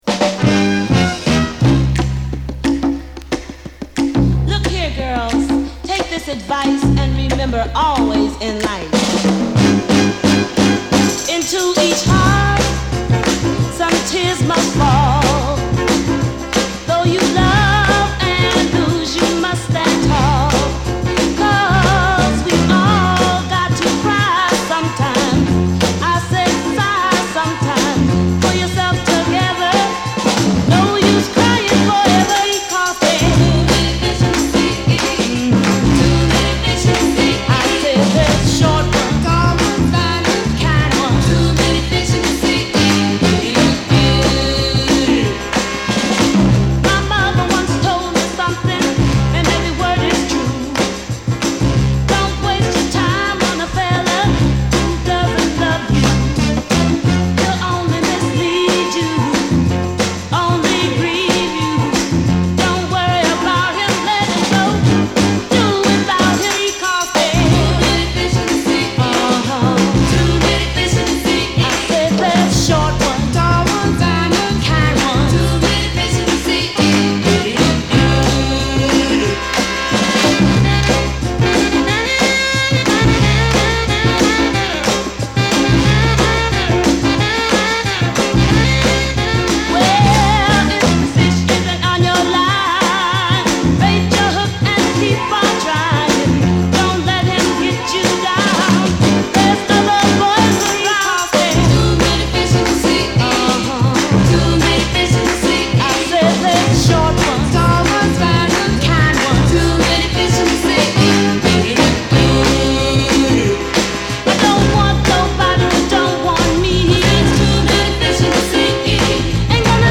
girls group